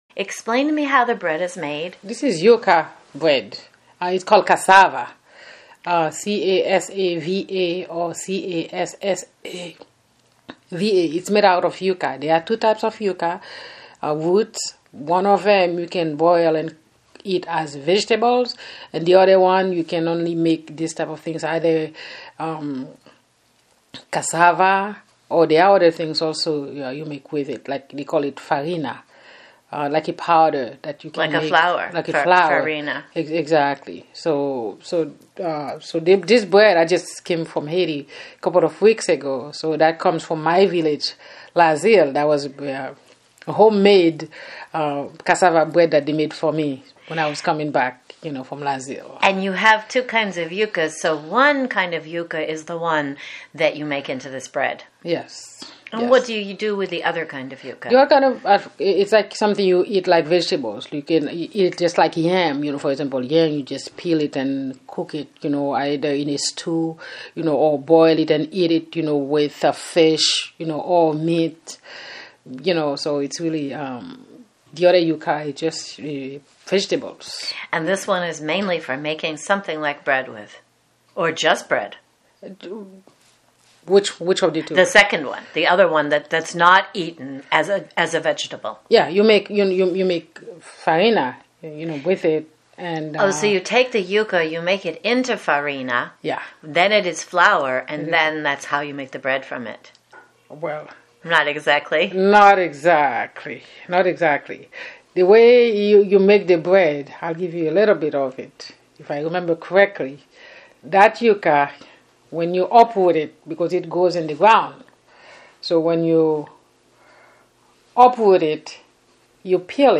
The Medford Haitian Oral History Project